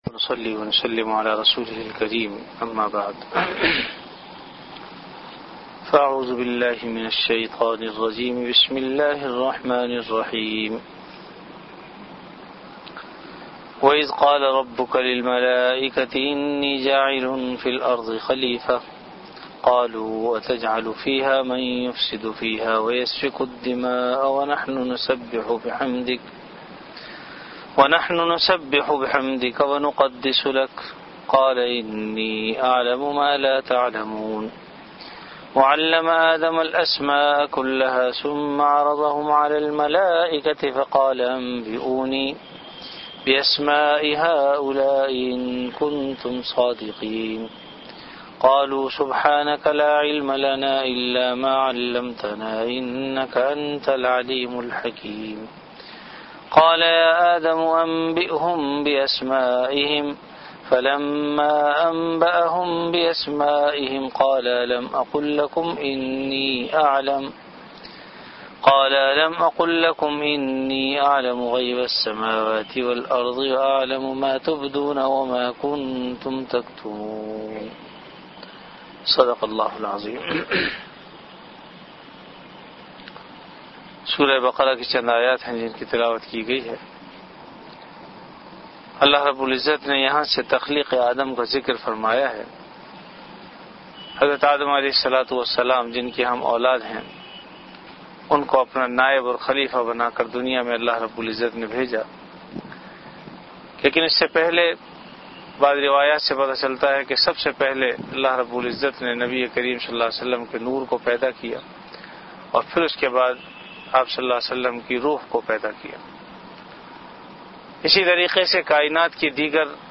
An Islamic audio bayan
Dars-e-quran · Jamia Masjid Bait-ul-Mukkaram, Karachi